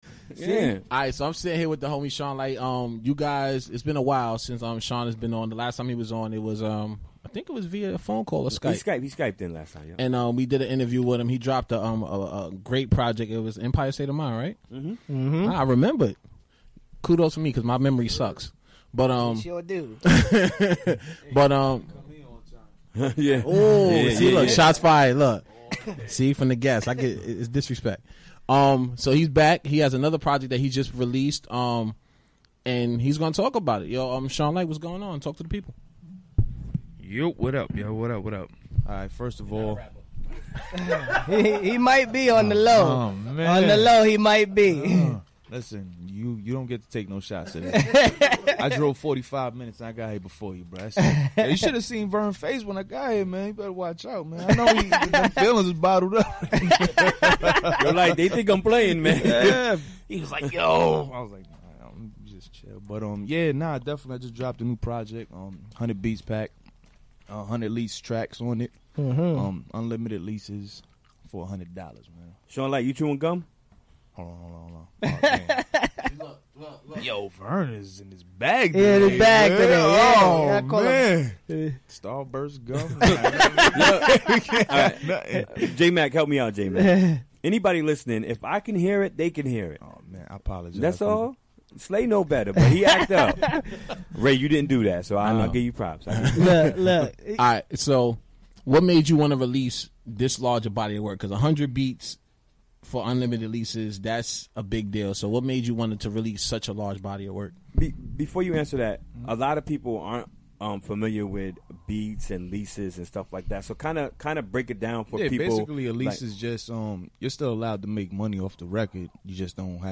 Recorded during the WGXC Afternoon Show Wednesday, May 3, 2017.